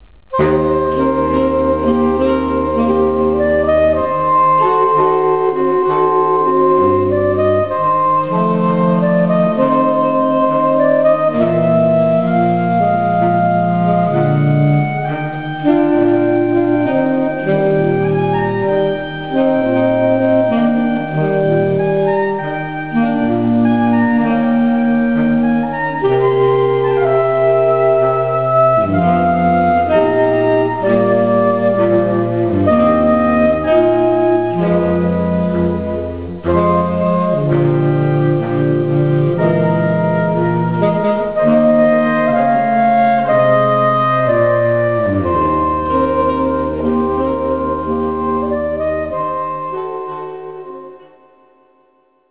A sax quartet can be as smooth as you like.